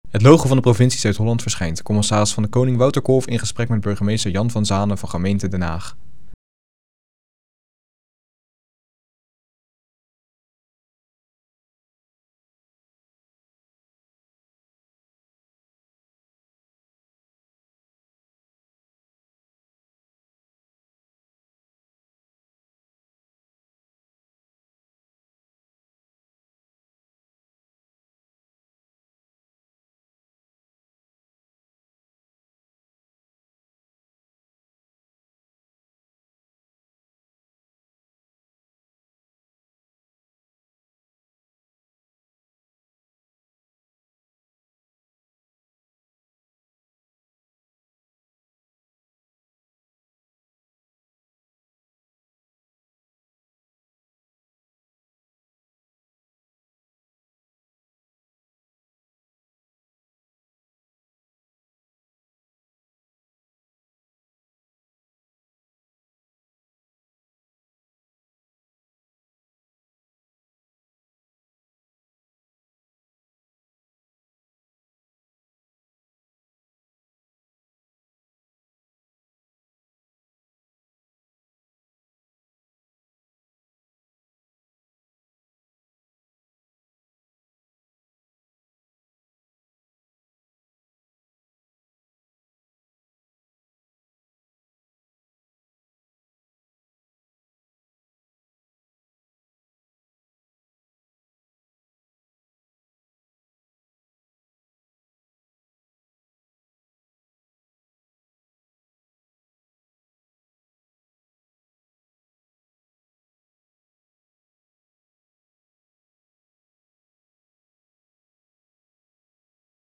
CdK in gesprek met burgemeester Den Haag